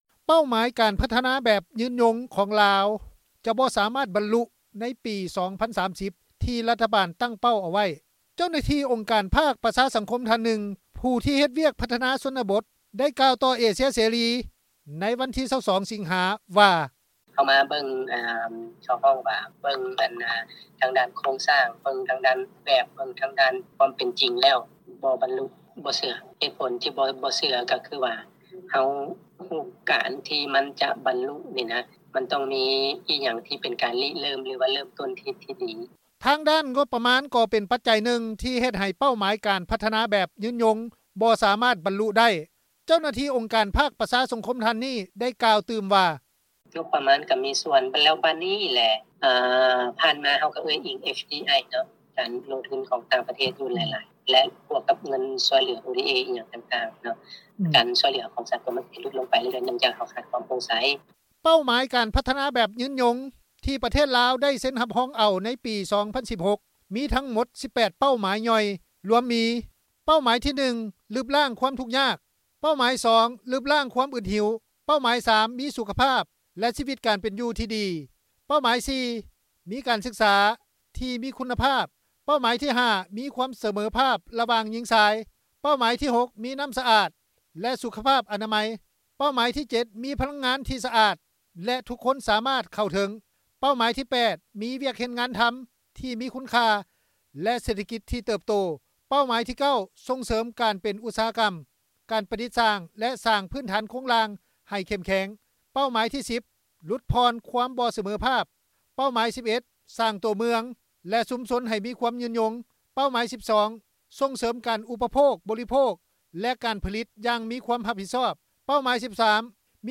ຊາວແຂວງອັດຕະປື ທ່ານນຶ່ງ ໄດ້ກ່າວຕໍ່ເອເຊັຽເສຣີ ໃນວັນທີ 22 ສິງຫາ ວ່າ:
ຊາວແຂວງຫົວພັນ ທ່ານນຶ່ງ ໄດ້ກ່າວຕໍ່ເອເຊັຽເສຣີ ວ່າ: